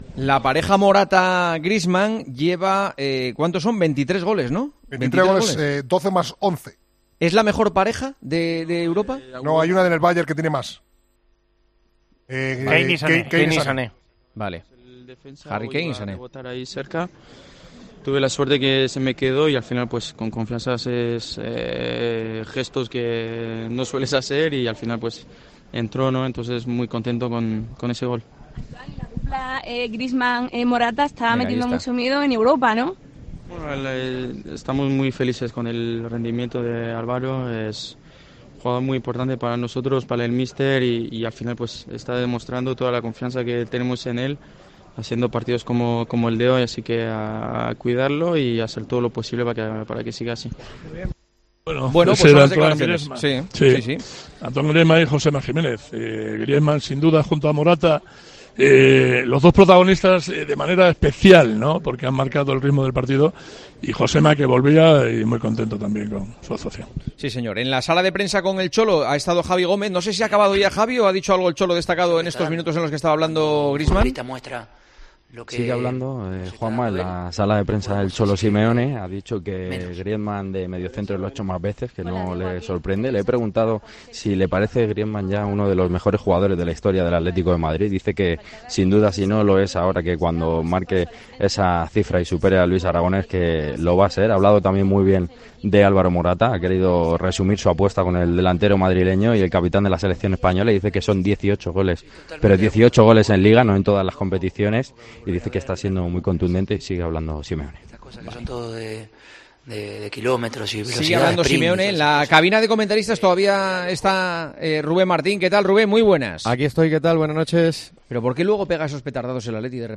Juanma Castaño lanzó una pregunta en la que todos los tertulinos de El Partidazo estuvieron de acuerdo de forma unánime.